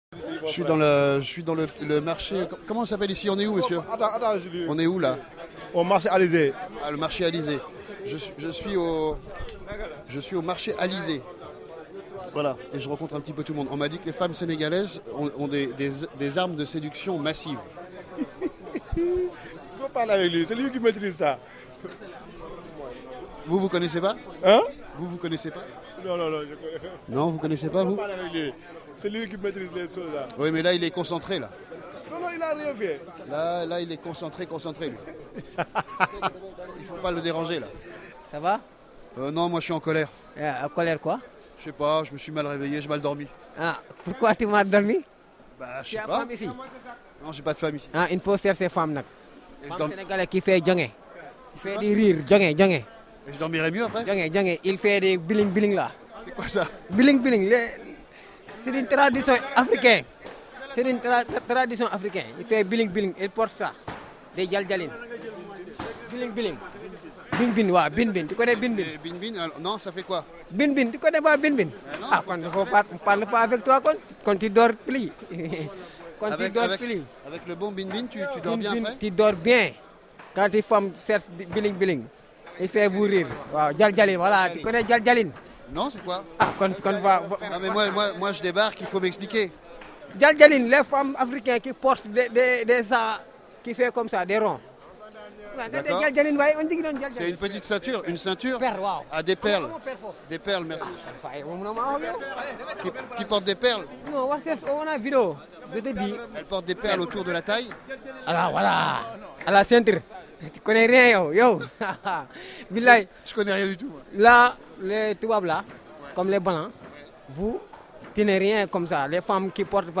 Au marché Alizé de Dakar, rencontre de hasard et découverte du Bin Bin !